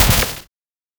Royalty-free bug sound effects
Generate a 150-200 ms punchy collision sound of a small green pixel-art bug slamming into a massive bushy boss: core warm mid-range “thock”, layered organic crunchy snap, subtle metallic shimmer, tiny digital glitch tail, lo-fi 16-bit saturation, no rever 0:01